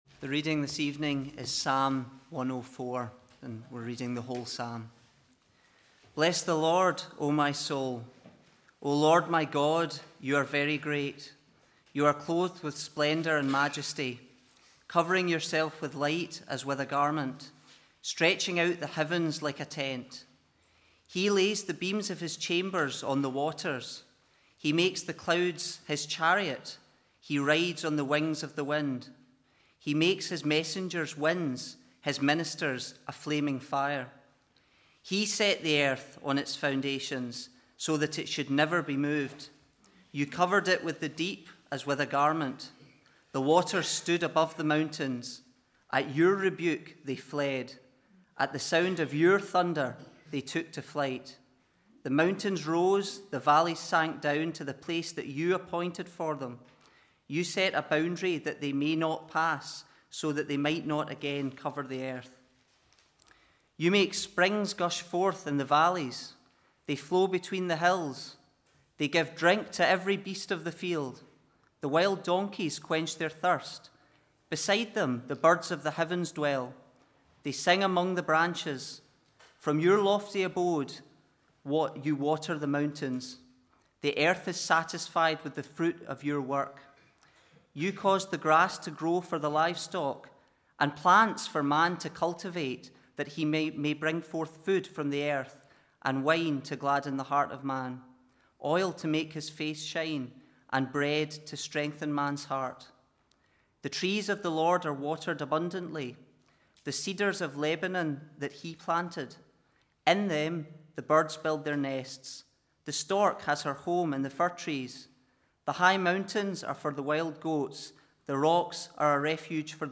2022 Autumn Lectures